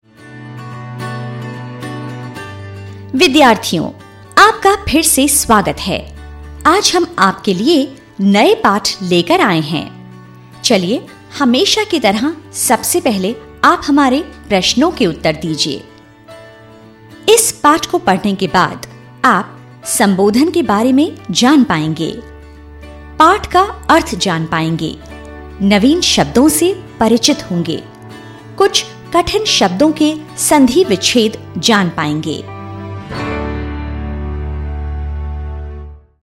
Amostras de Voz Nativa